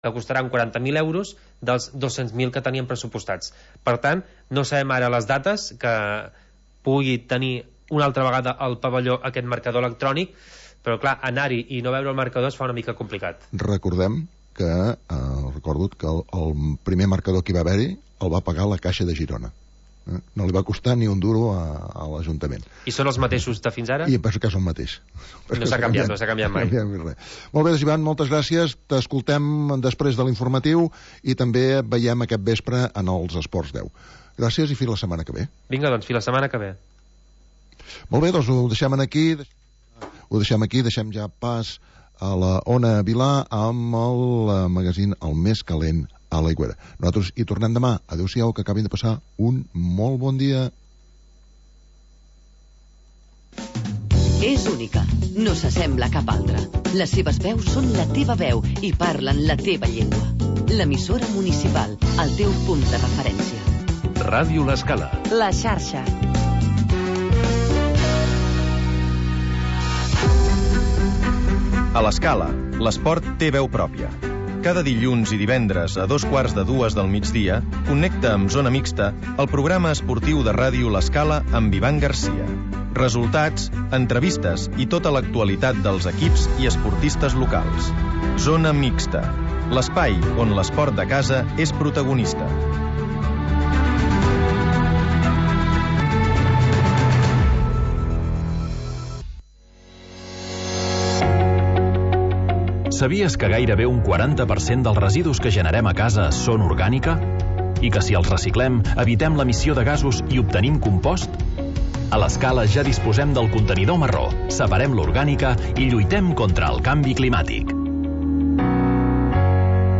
Magazín d'entreteniment